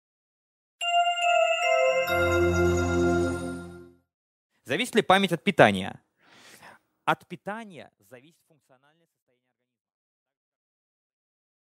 Аудиокнига Зависит ли память от питания?